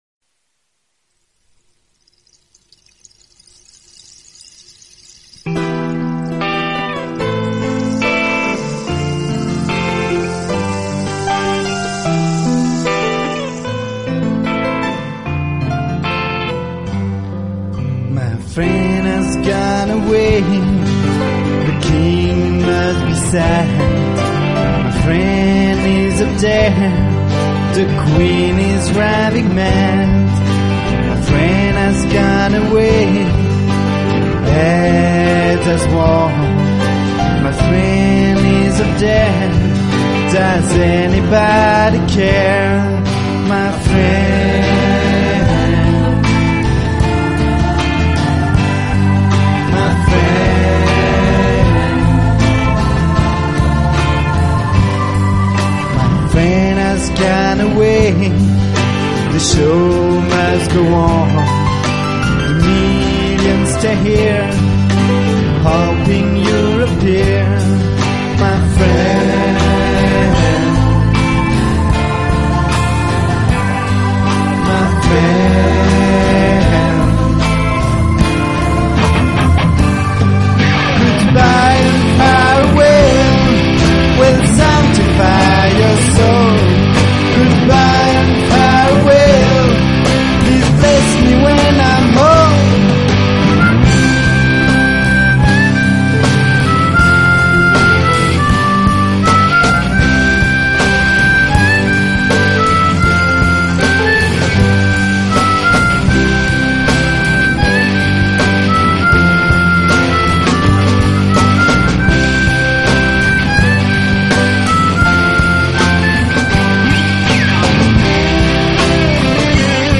voix, claviers, guitares,basse et programmation batterie
La Fiction pop-rock